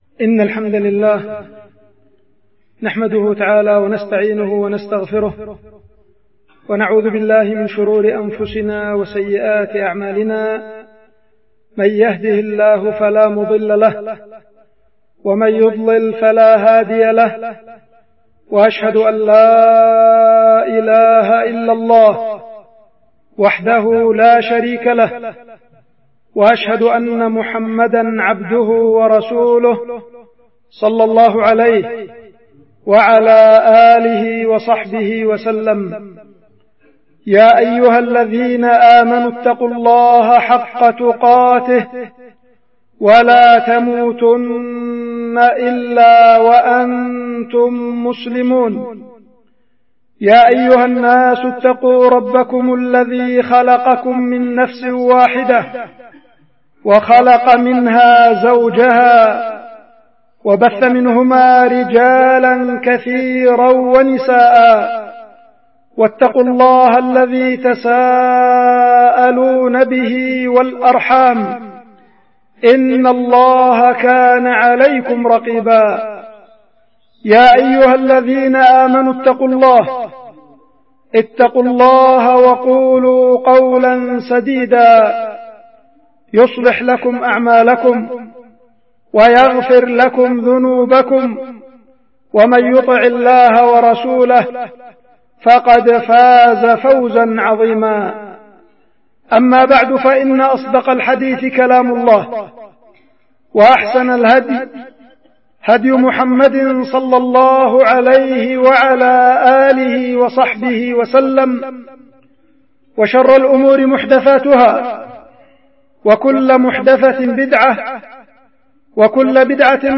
خطبة
القيت في الجامع الكبير بالعزلة- مديرية بعدان- إب-اليمن